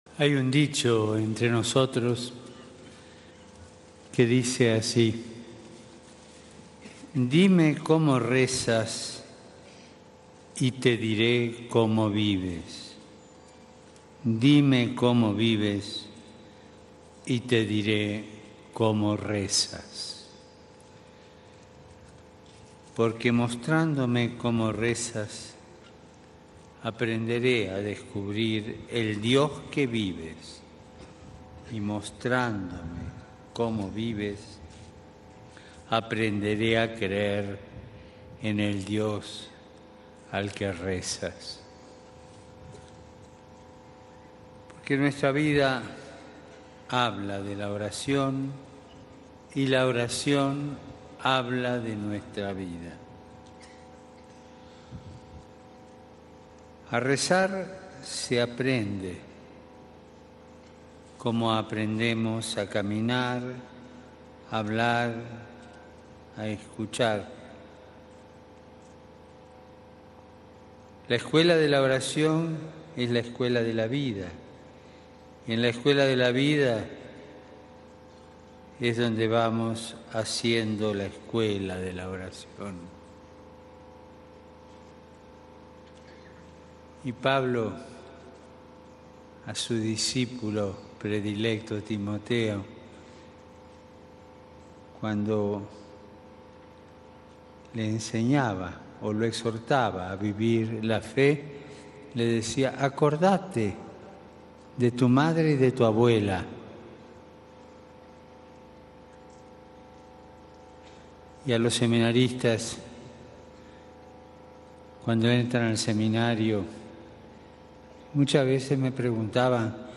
Misionando en México la Misericordia y la Paz de Cristo, en el XII Viaje Apostólico internacional de su Pontificado, el Papa Francisco, en la cuarta y penúltima jornada de su peregrinación, celebró la Santa Misa con sacerdotes, religiosos, religiosas, consagrados y seminaristas en Morelia, en el estadio «Venustiano Carranza», donde fue recibido con gran alegría y fervor.